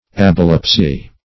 ablepsy - definition of ablepsy - synonyms, pronunciation, spelling from Free Dictionary Search Result for " ablepsy" : The Collaborative International Dictionary of English v.0.48: Ablepsy \Ab"lep*sy\, n. [Gr.